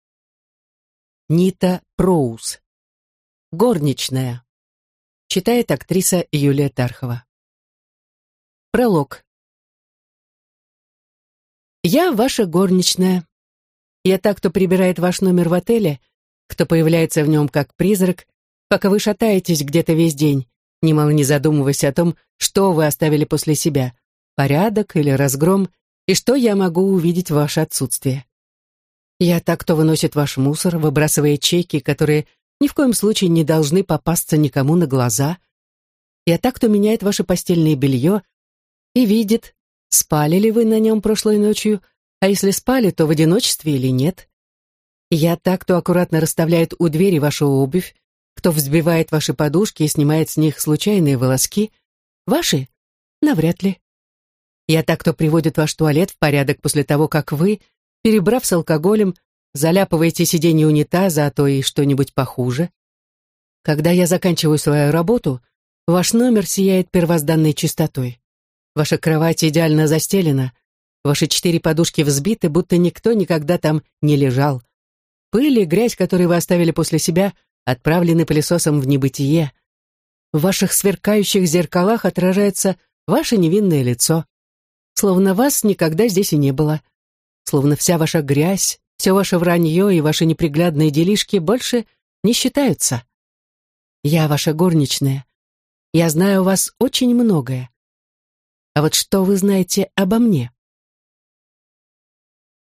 Аудиокнига Горничная | Библиотека аудиокниг